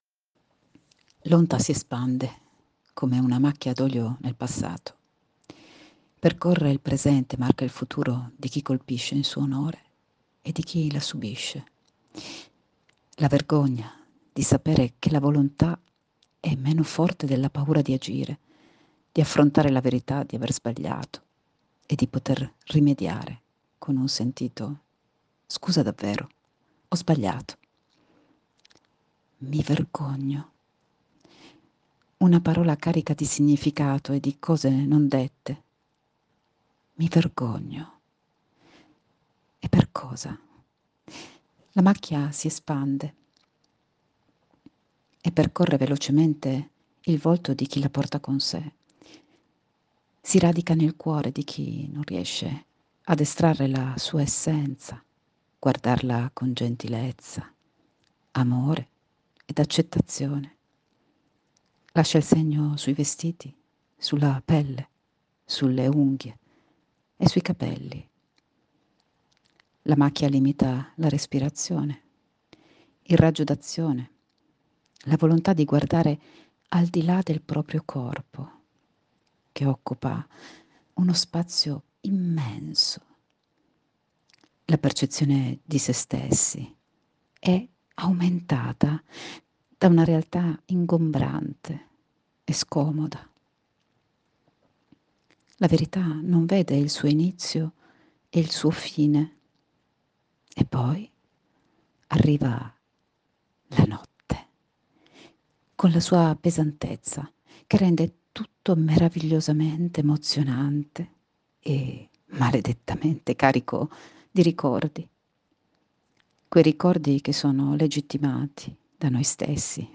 Lettura al link che segue